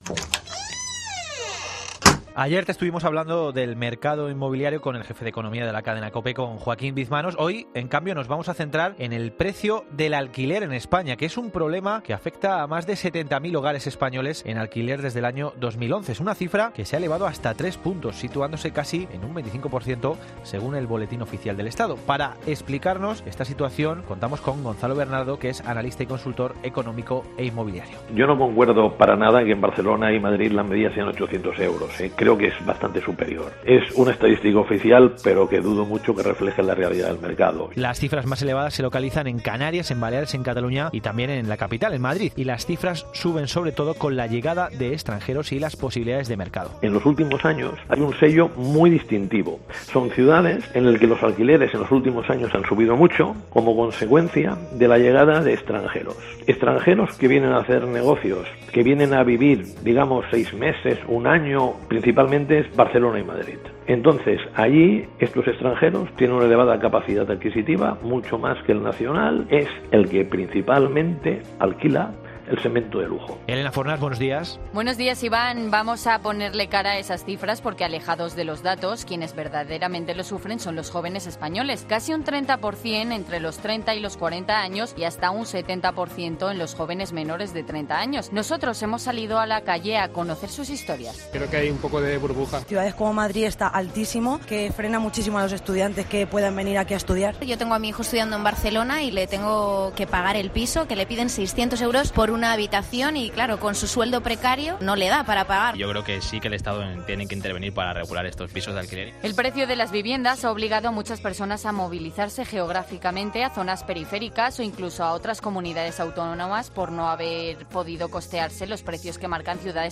Nosotros hemos salido a la calle a conocer sus historias...